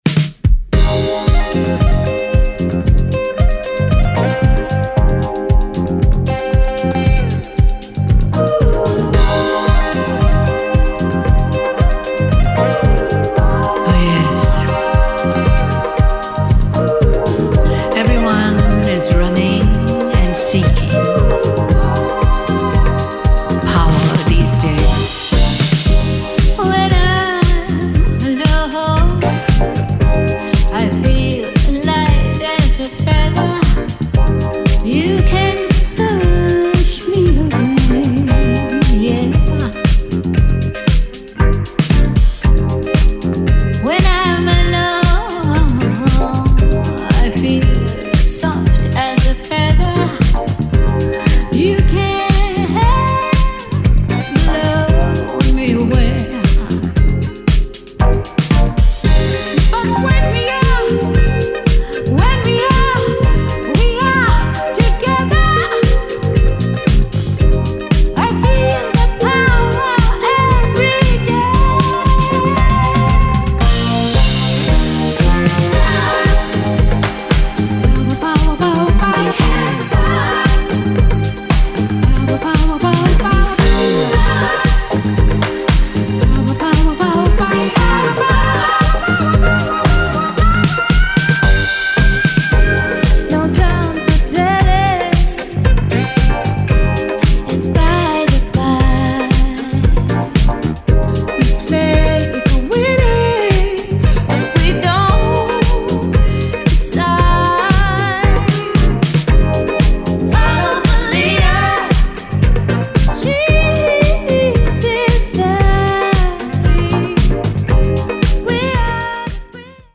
どちらもよりモダンでダビーなエレクトリック・ディスコへと昇華してみせた、全バージョン楽しめる1枚に仕上がっています。